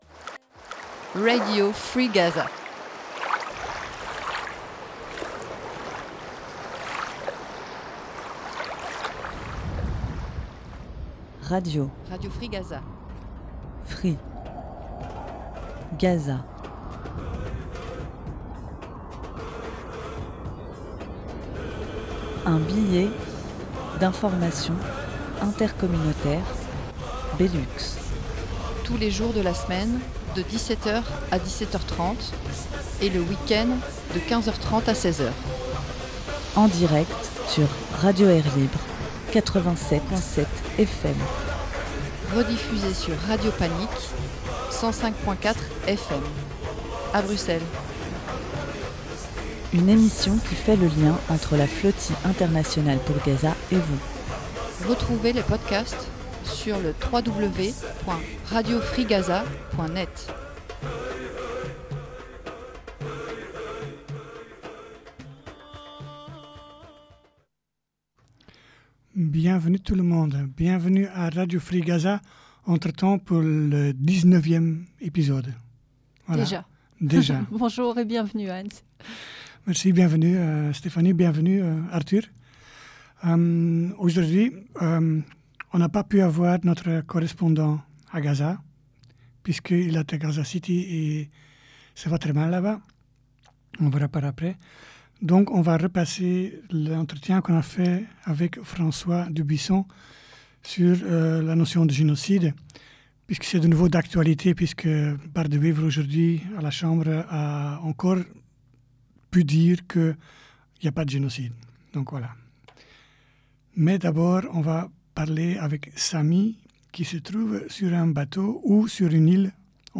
qui se trouve sur un bateau entre Pantellaria et Sicile